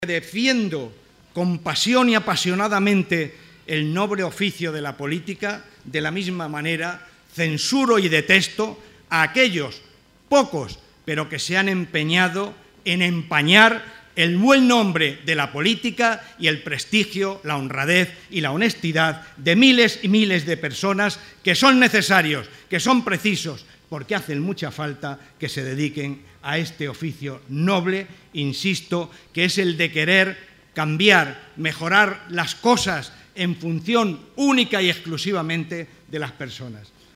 Barreda junto a Rivas en el acto celebrado en Talavera.